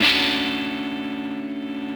ChordDmaj7.wav